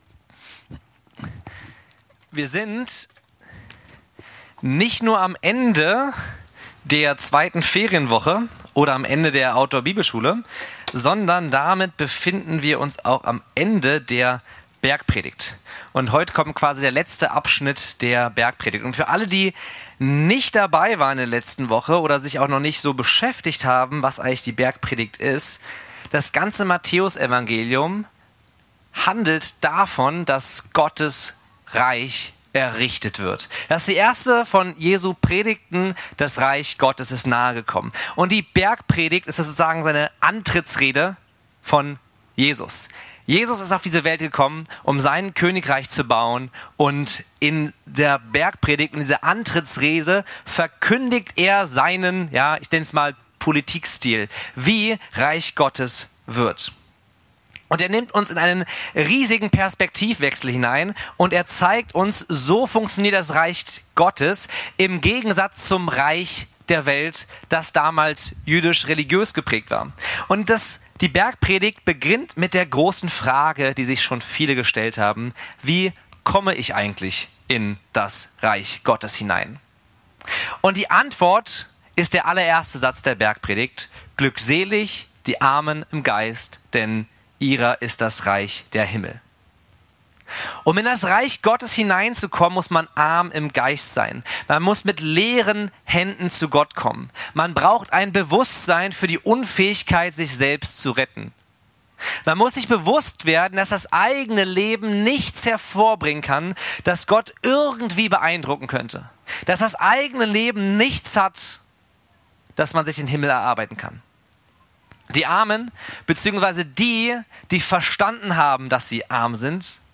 Focused Abschlusspredigt